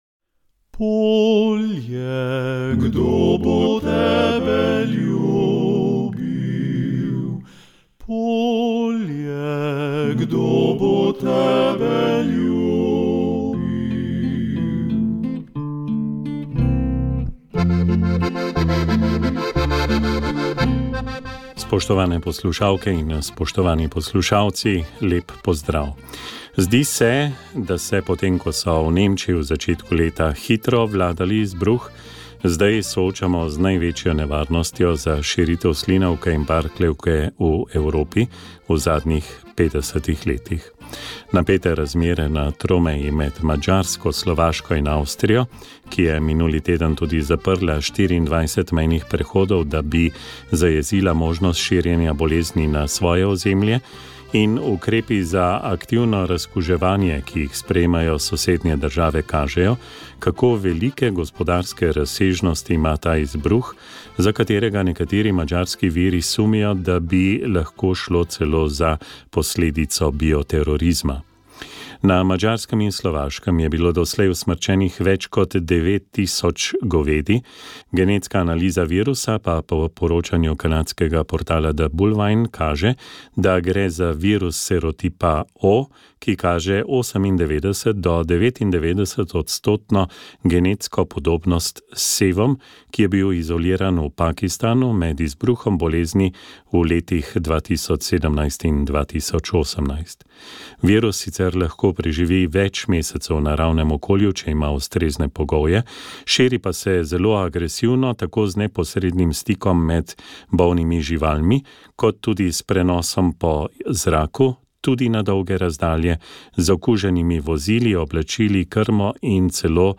pel je cerkveni zbor iz bližnjih Hodiš. Na praznik je bilo veselo tudi na Farantu v Globasnici.